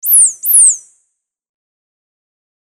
鳥の鳴き声 effect 1
/ F｜演出・アニメ・心理 / F-75 ｜other 生音の再現 / 50_other_鳴き声